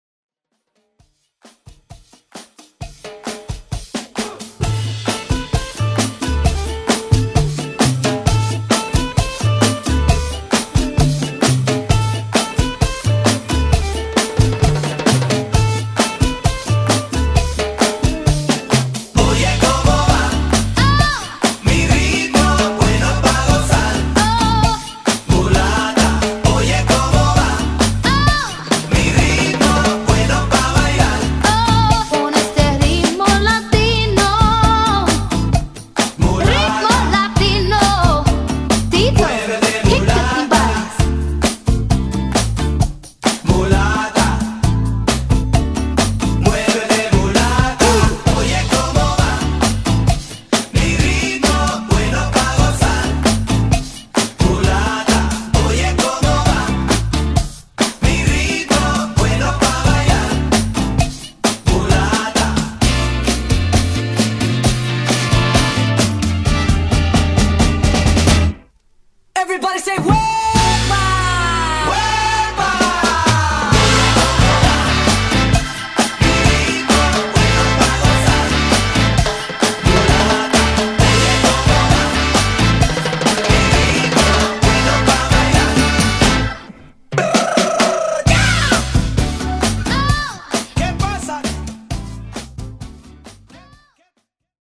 Genre: OLD SCHOOL HIPHOP
Clean BPM: 94 Time